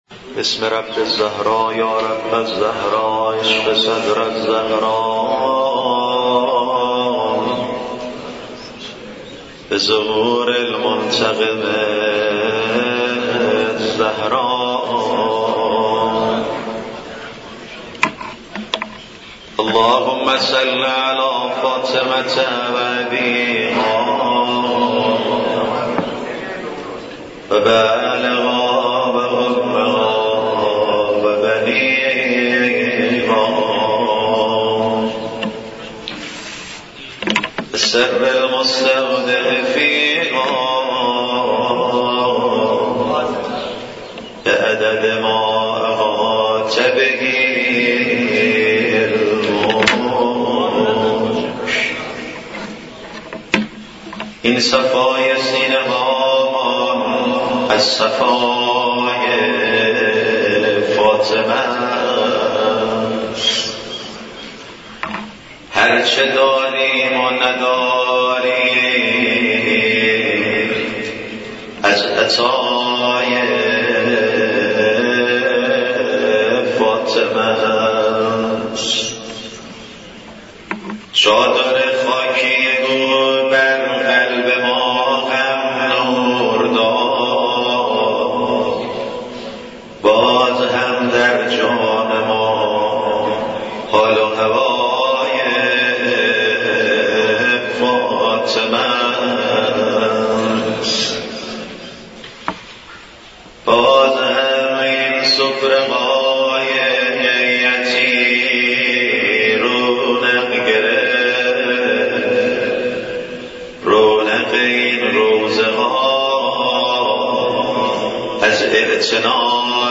برگزاری مراسم عزاداری شهادت حضرت فاطمه زهرا سلام الله علیها در مسجد دانشگاه کاشان
مدّاحی